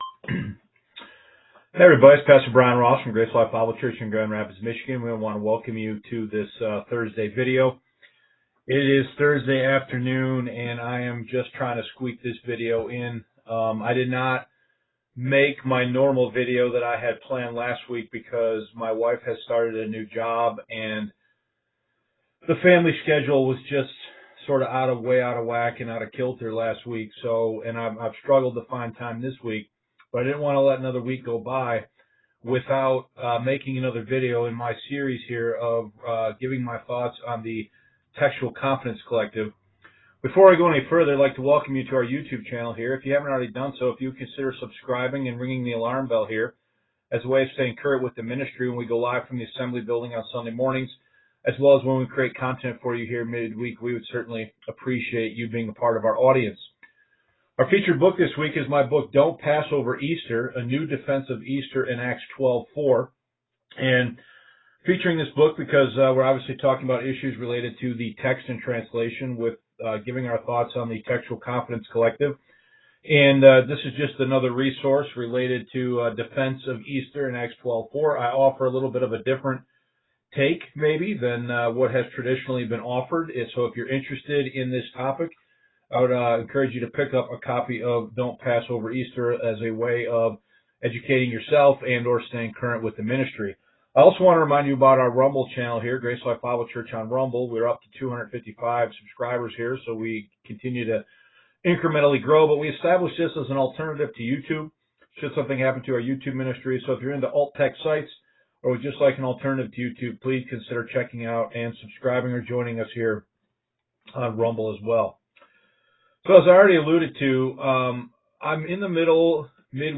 Facebook Live Videos (Vlogs) , Mid-Week Messages